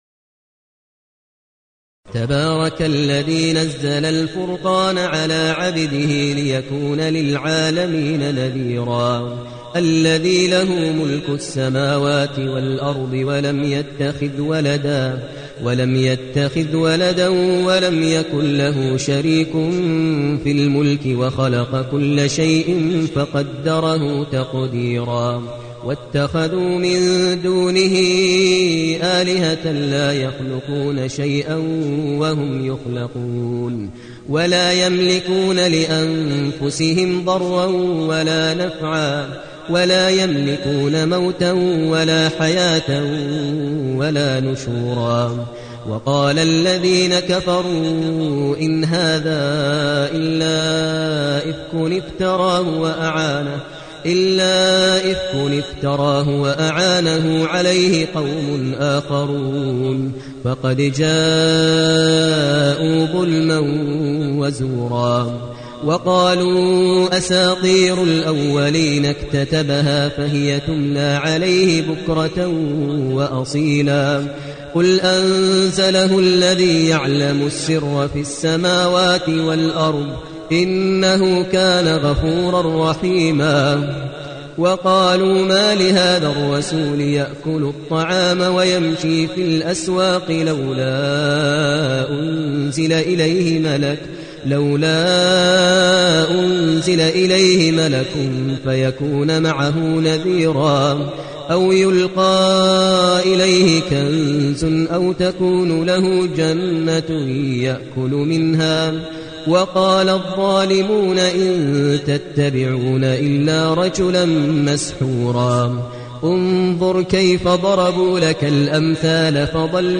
المكان: المسجد النبوي الشيخ: فضيلة الشيخ ماهر المعيقلي فضيلة الشيخ ماهر المعيقلي الفرقان